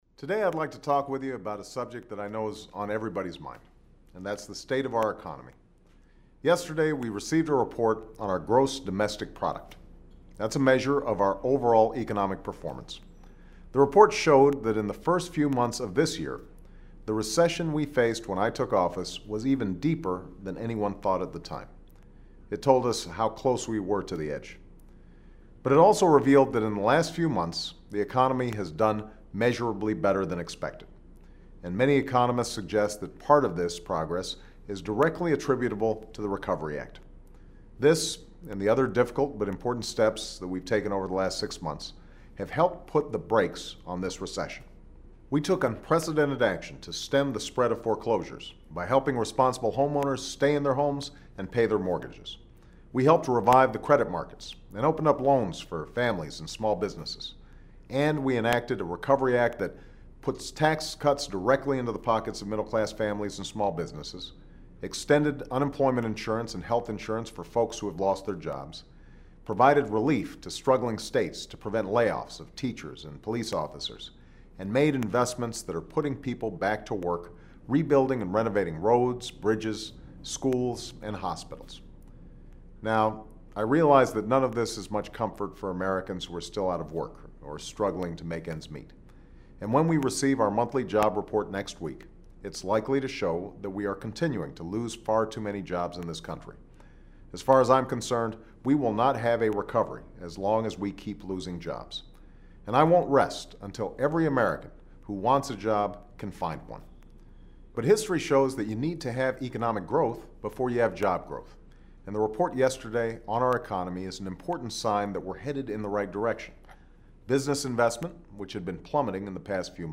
Weekly Address: This Economic Storm Will Pass